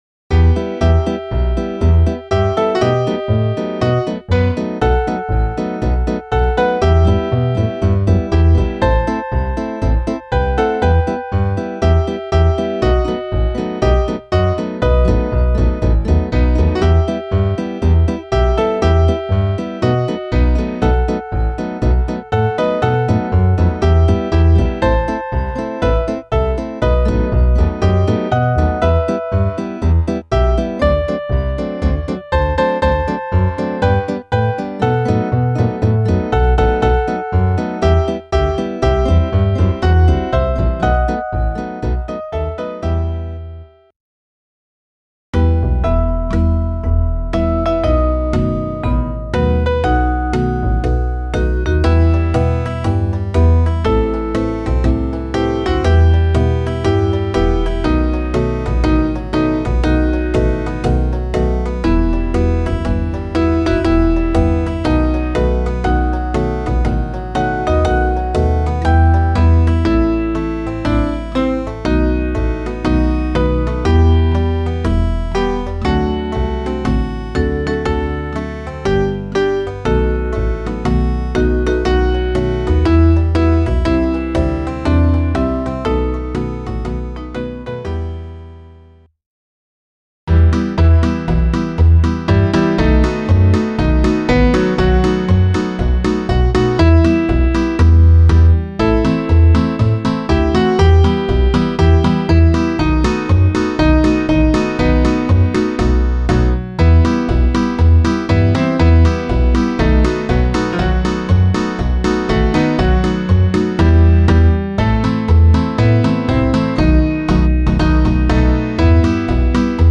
Variationen